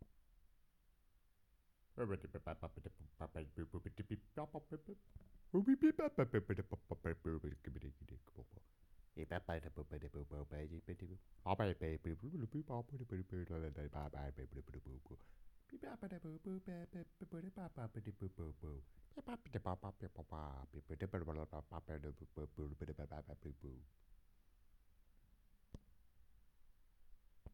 Babble / bla bla bla in stereo / comic / Cartoon
babble babbling bla blah cartoon cartoony gibberish human sound effect free sound royalty free Movies & TV